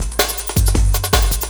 06 LOOP10 -R.wav